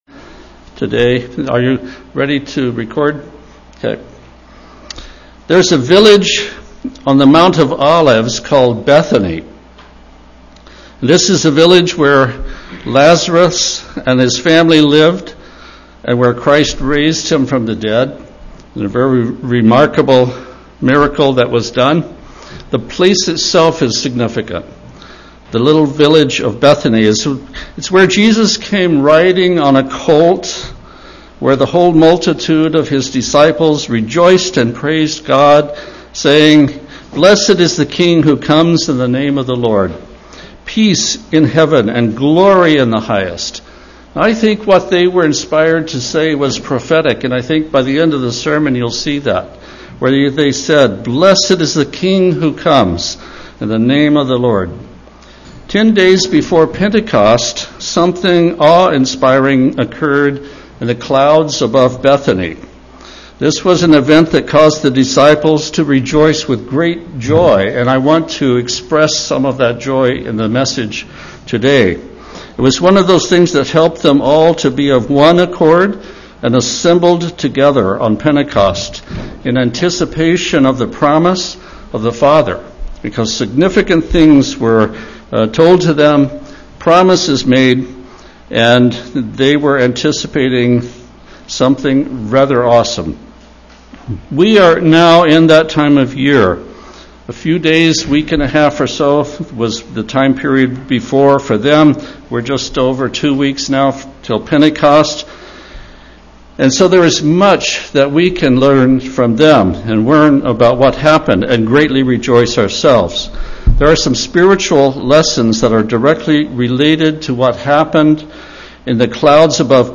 Inspiring passages of Scripture speak of this event, either prophetically or retrospectively. This "split (short) sermon" considers some of those truths.